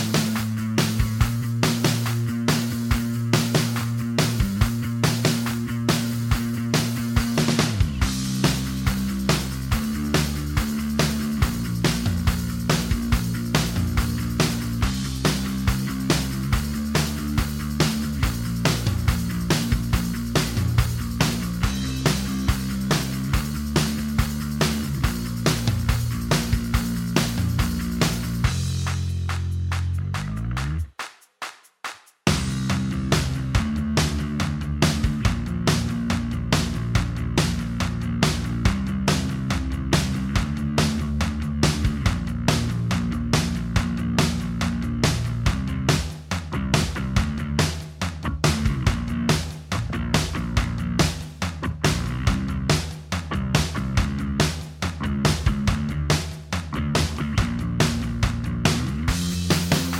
Minus Main Guitars For Guitarists 3:26 Buy £1.50